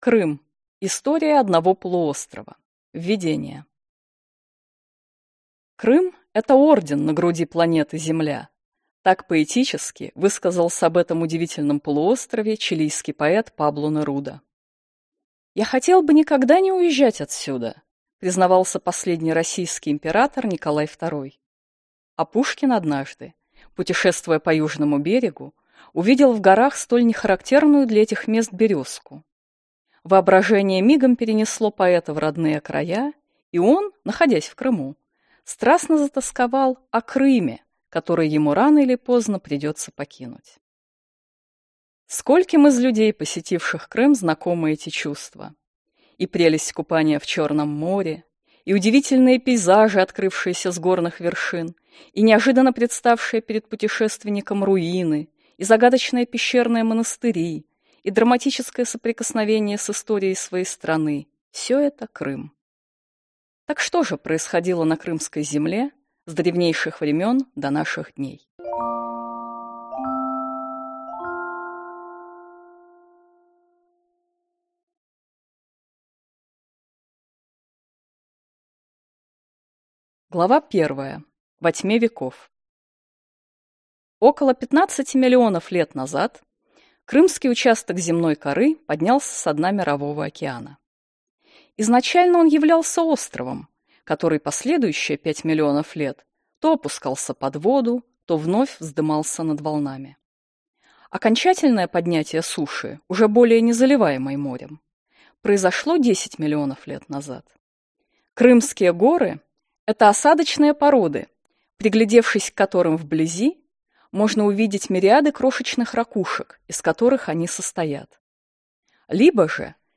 Аудиокнига КРЫМ. История одного полуострова | Библиотека аудиокниг